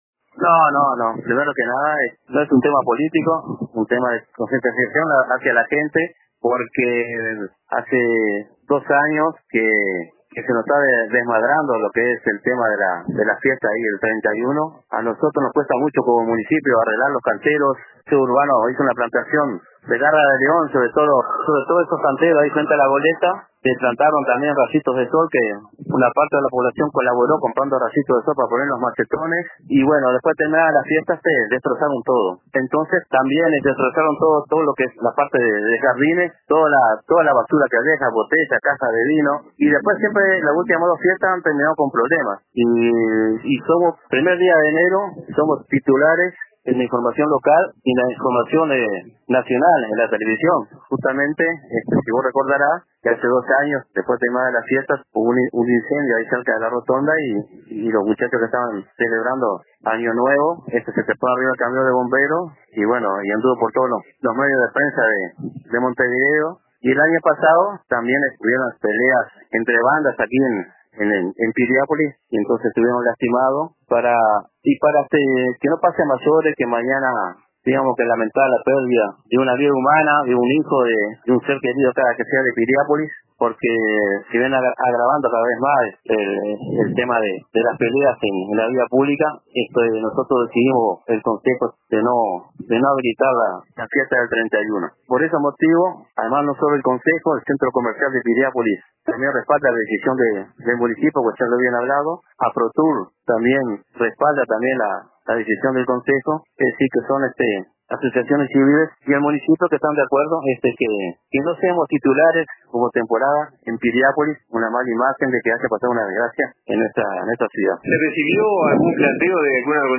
El alcalde René Graña explicó a RADIO RBC que la decisión se tomó en base a los destrozos en las plantas de los canteros de la Rambla, la basura que dejan los asistentes y los problemas que han ocurrido en las últimas dos ediciones.